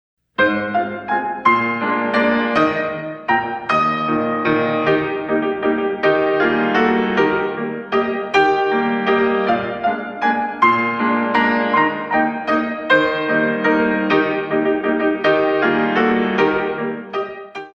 In 3
32 Counts